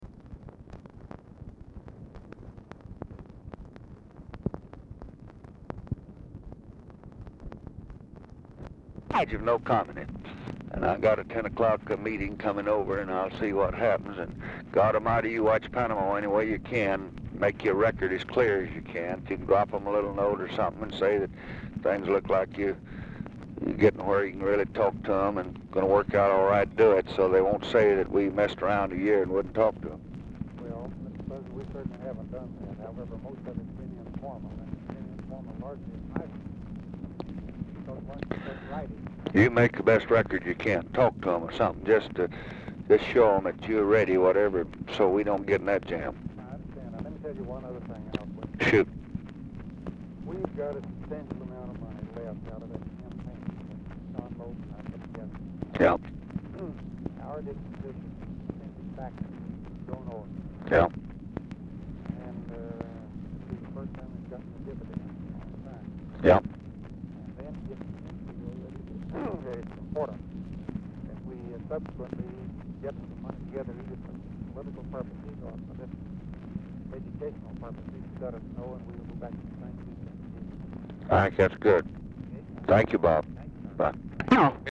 Telephone conversation # 7721, sound recording, LBJ and ROBERT ANDERSON
ANDERSON IS ALMOST INAUDIBLE
Format Dictation belt
Location Of Speaker 1 Mansion, White House, Washington, DC